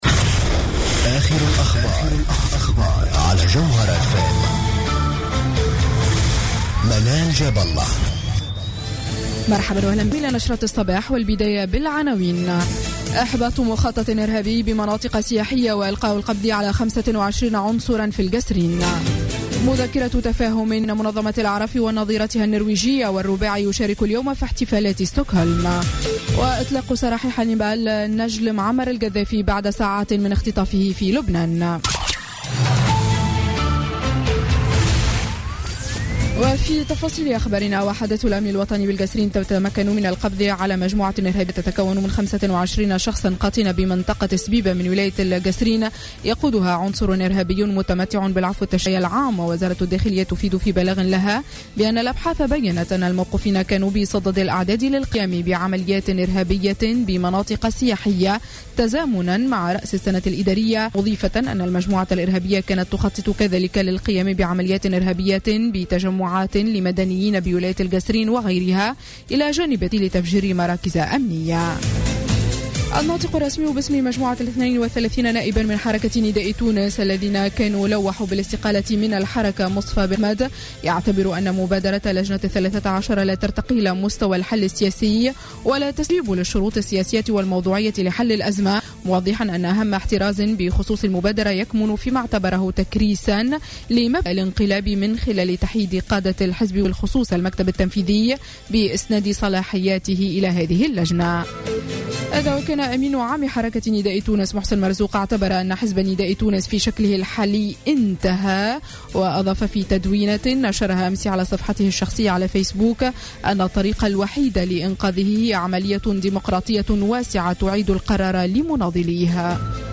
نشرة أخبار السابعة صباحا ليوم السبت 12 ديسمبر 2015